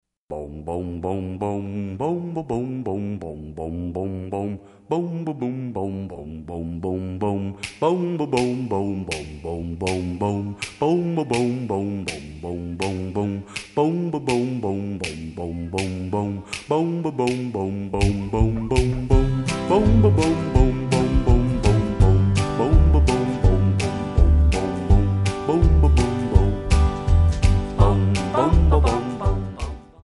MPEG 1 Layer 3 (Stereo)
Backing track Karaoke
Pop, Oldies, 1950s